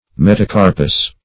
Metacarpus \Met`a*car"pus\, n. [NL., fr. Gr.
metacarpus.mp3